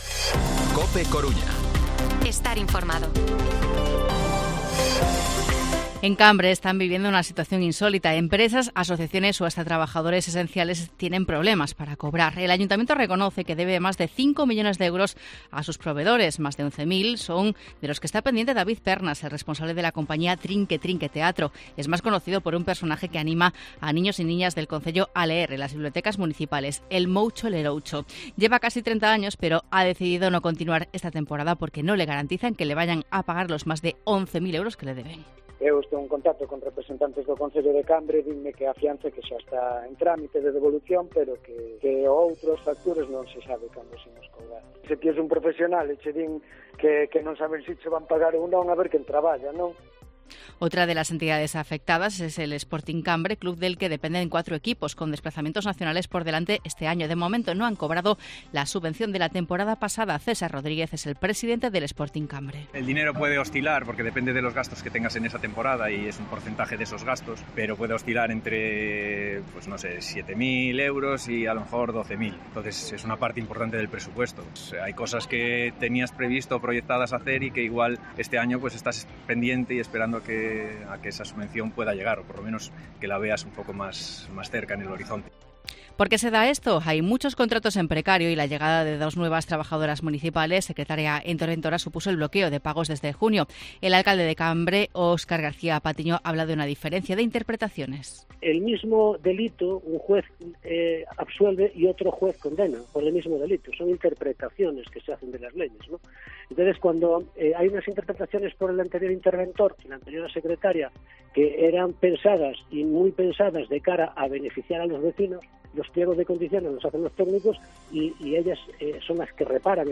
Impagos en Cambre: hablan algunos afectados